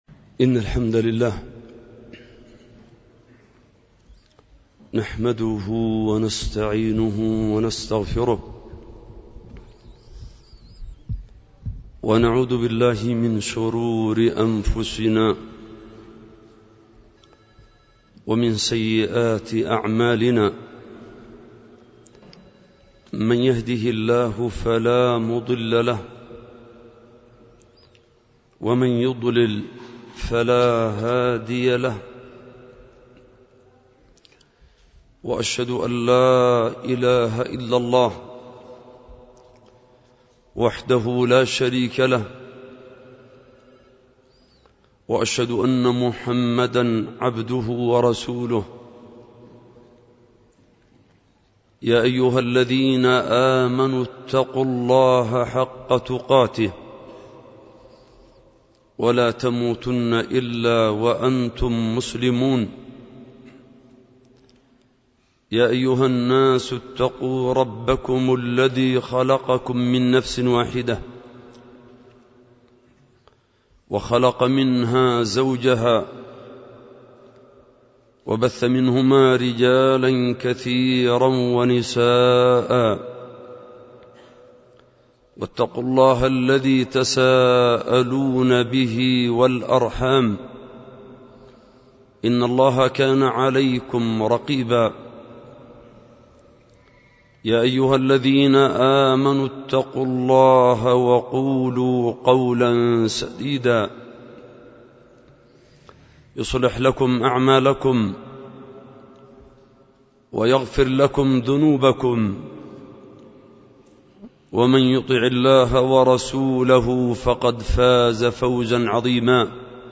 قراءة لرسالة مشاهداتي في دار الإمام الوادعي